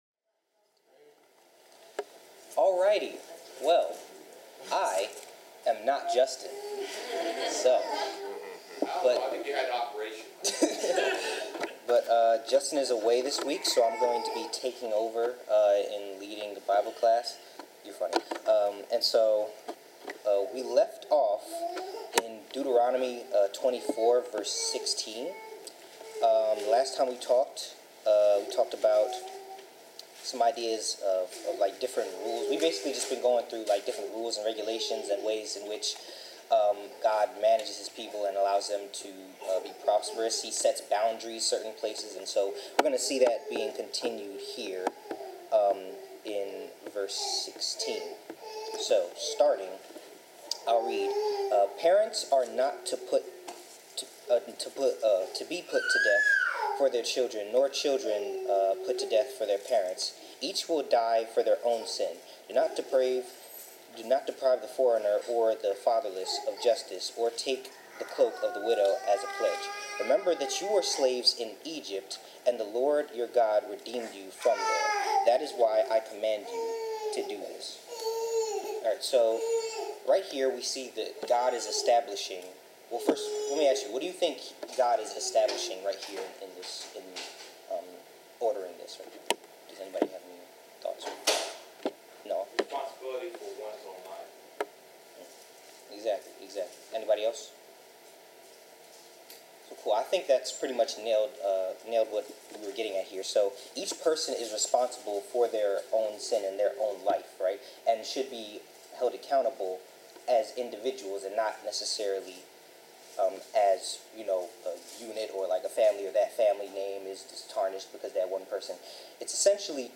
Bible class: Deuteronomy 24-25
Passage: Deuteronomy 24:16-25:10 Service Type: Bible Class